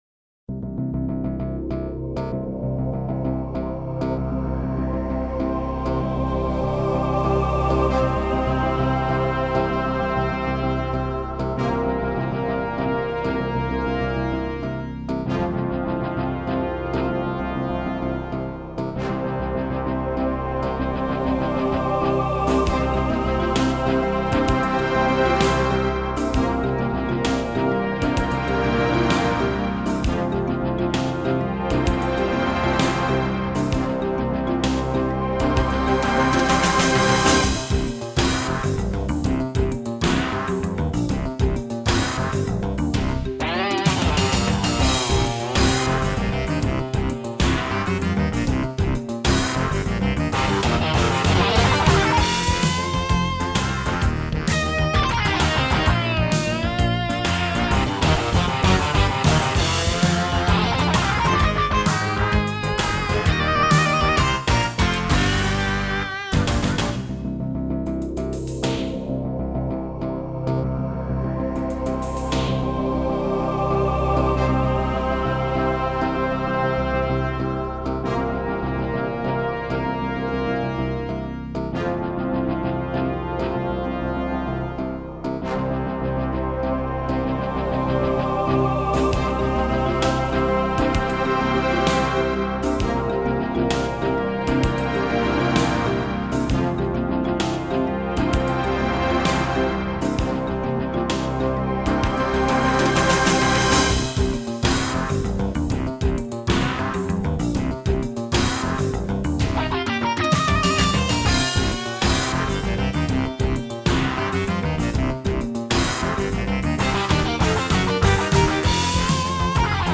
Very spacy with eerie choir vocal sounds
This one is fun to listen to on headphones.